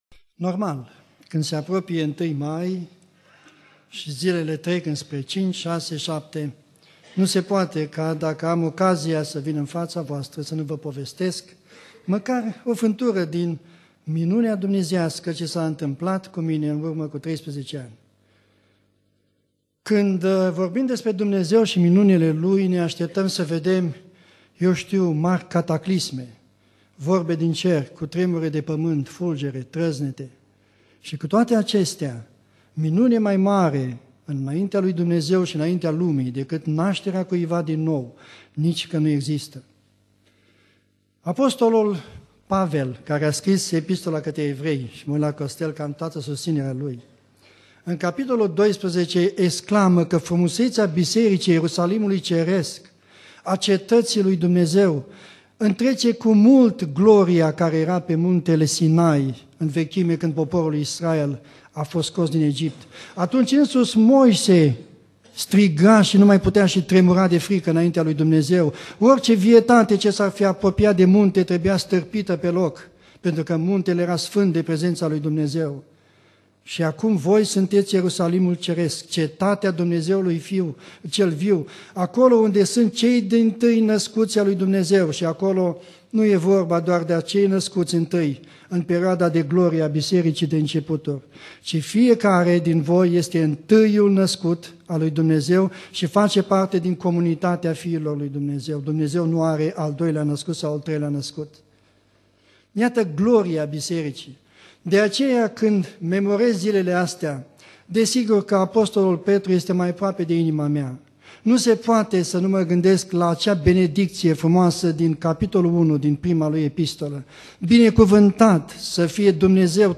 Predica Aplicatie - Isaia 60-62